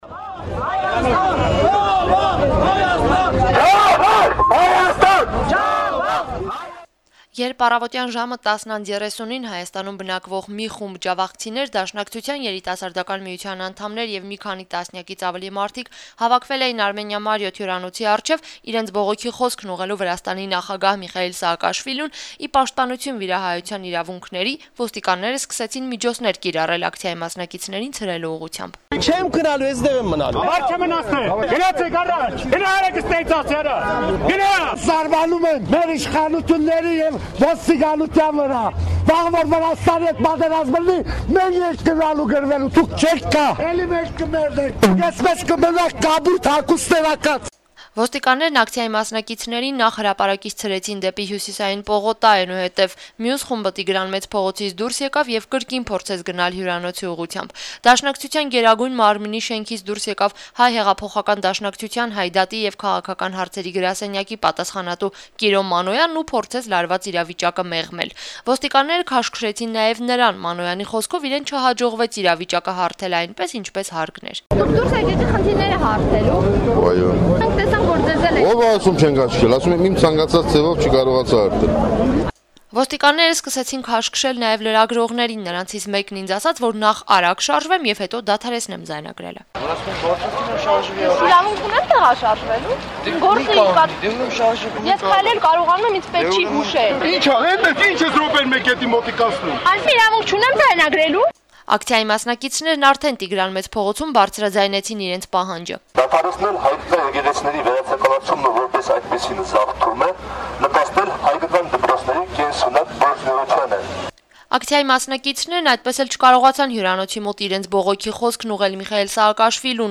Հինգշաբթի օրը, ջավախահայության խնդիրներով մտահոգ մի խումբ մարդիկ՝ Հայաստանում բնակվող ջավախքցիներ, Դաշնակցության երիտասարդական միության անդամներ եւ այլոք՝ մի քանի տասնյակից ավելի մարդիկ, կրկին հավաքվել էին բողոքի ցույցի՝ ի պաշտպանություն վիրահայության իրավունքների: